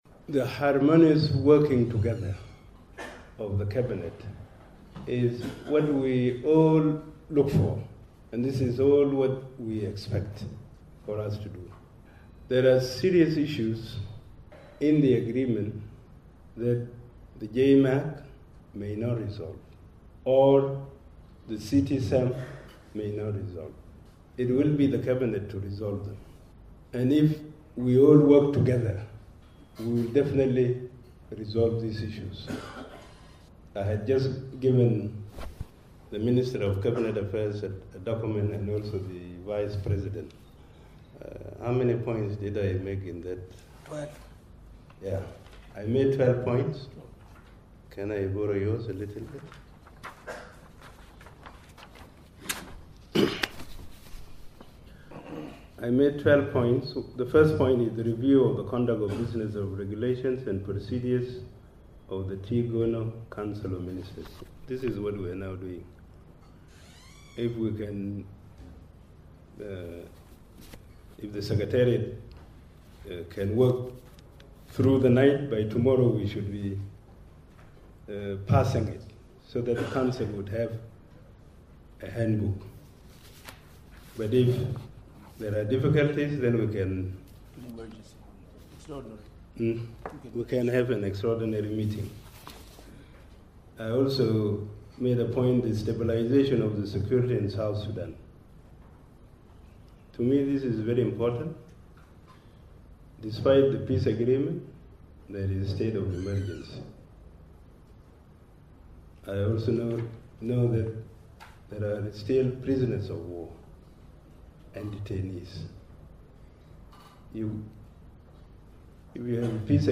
Machar was speaking at the closing of a one day induction training of the ministers into the Transitional Government.